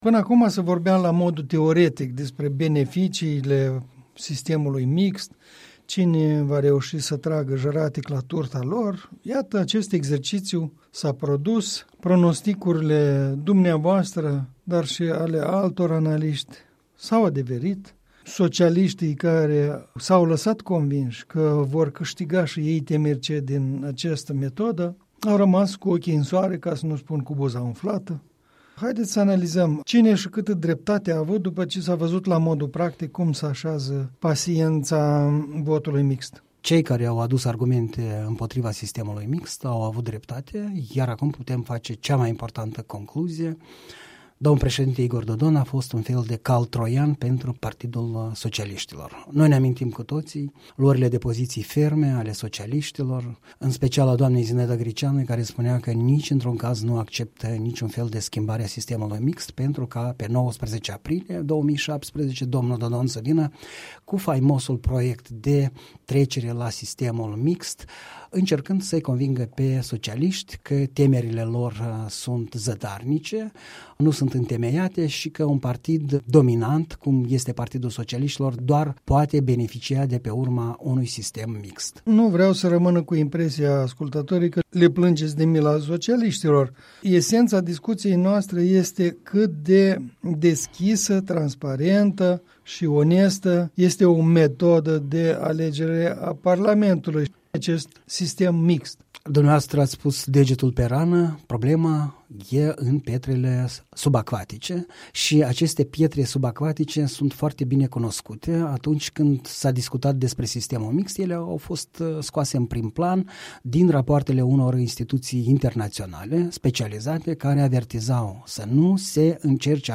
Un punct de vedere